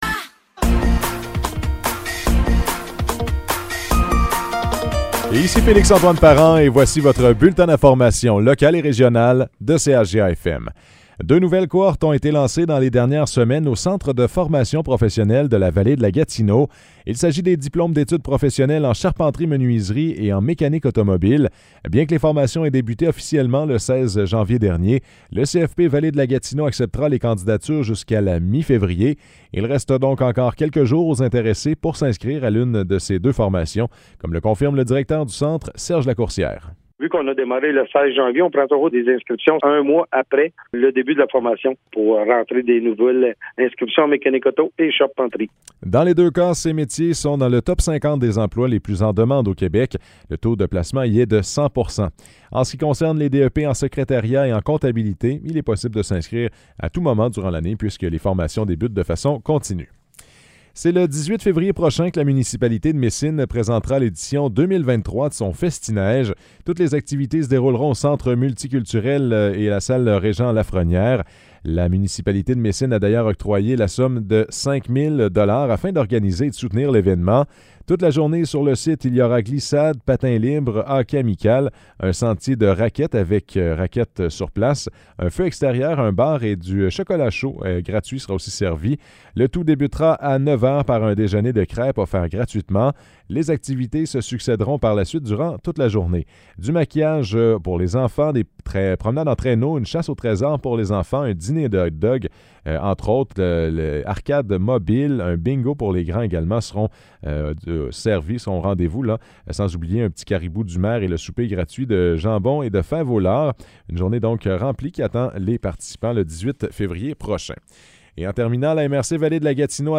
Nouvelles locales - 31 janvier 2023 - 15 h